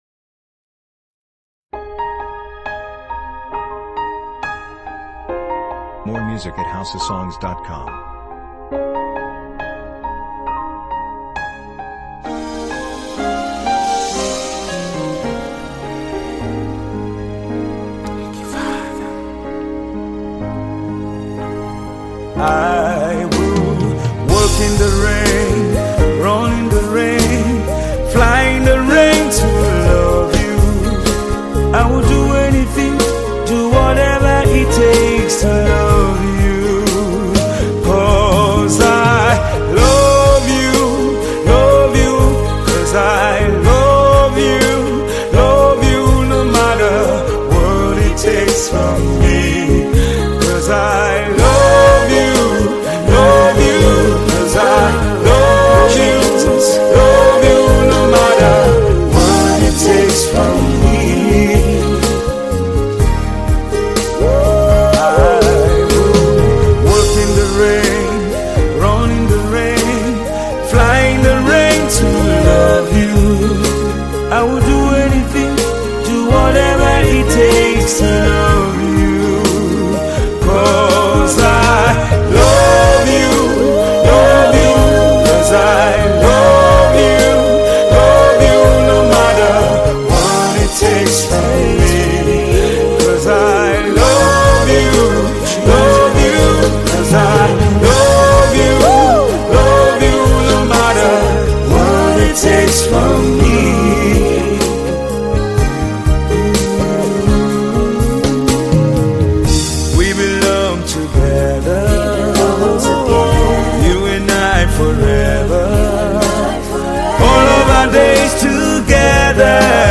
Tiv Song